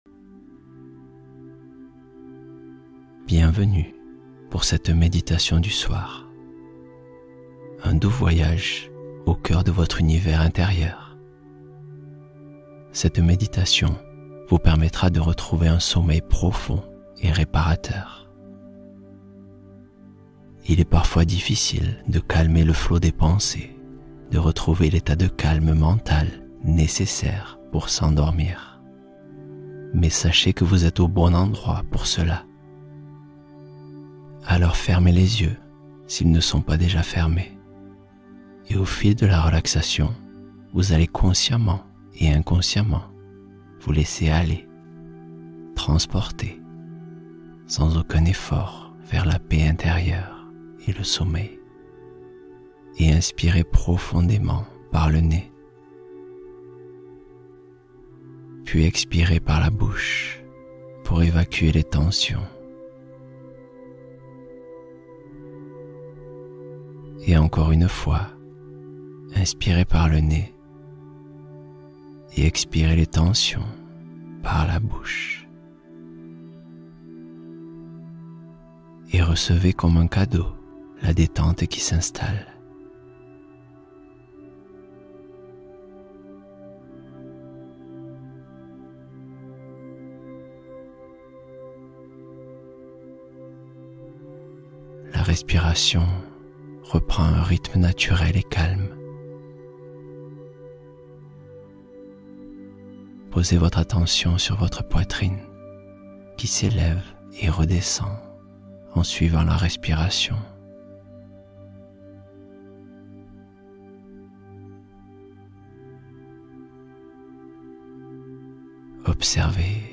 Méditation courte : lâcher prise instantané